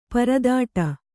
♪ paradāṭa